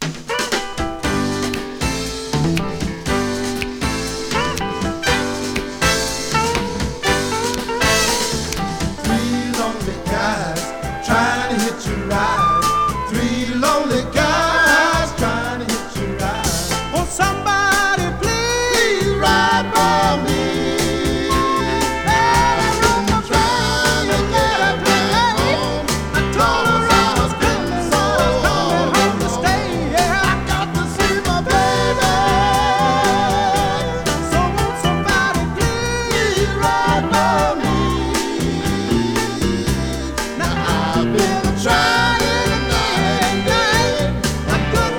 Soul　UK　12inchレコード　33rpm　Mono, Stereo